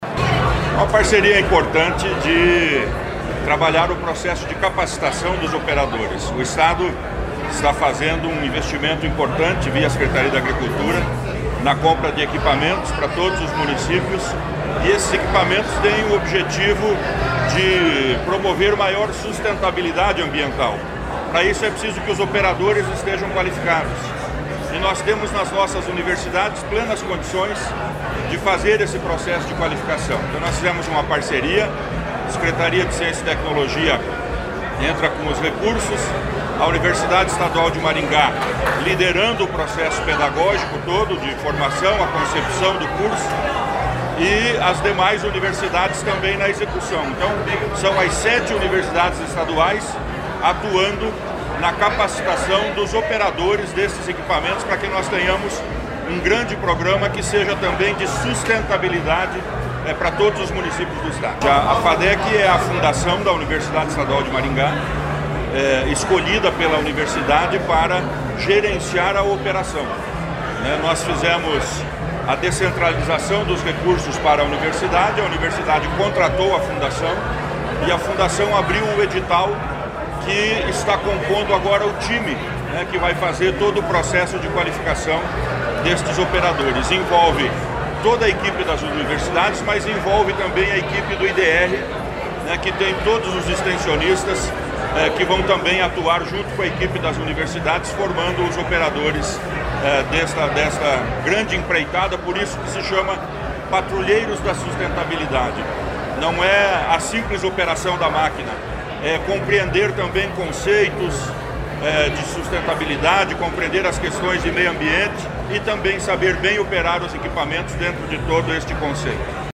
Sonora do secretário da Ciência, Tecnologia e Ensino Superior, Aldo Bona, sobre o programa Patrulheiros da Sustentabilidade